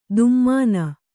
♪ dummāna